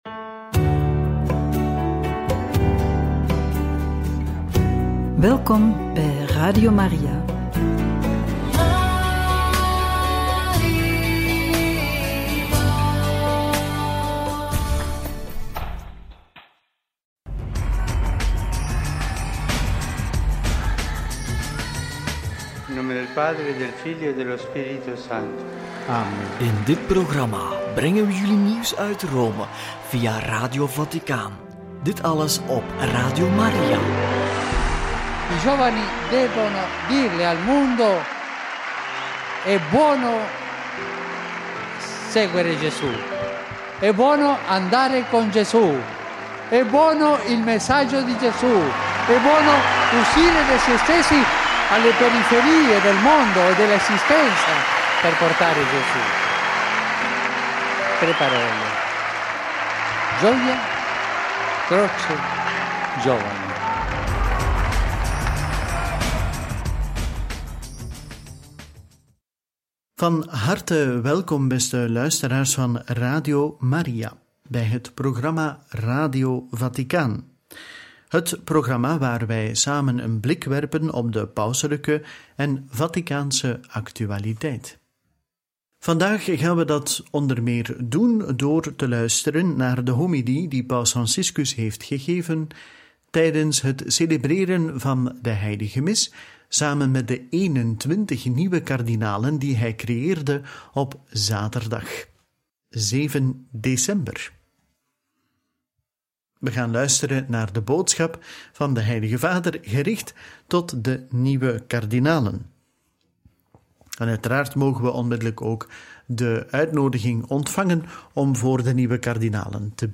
Kerstboom – en stal ingehuldigd – Homilie bij benoeming van 21 nieuwe kardinalen – Angelus 8/12 – Radio Maria